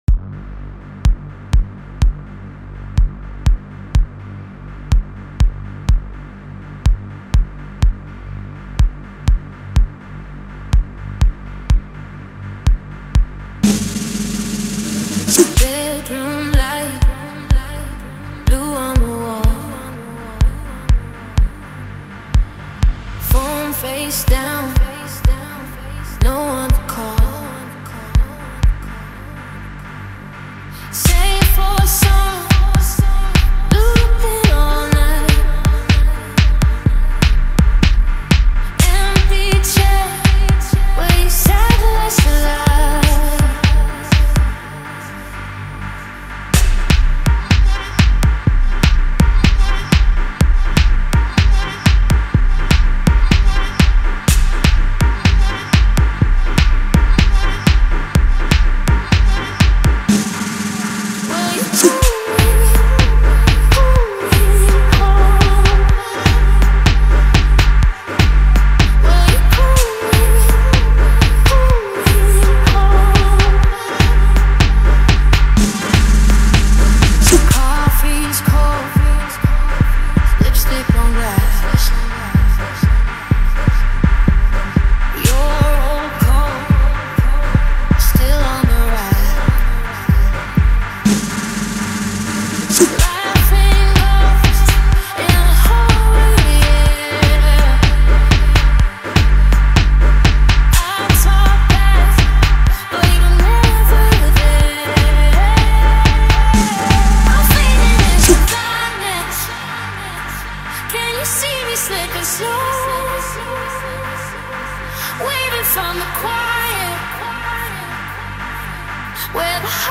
Home » Amapiano » DJ Mix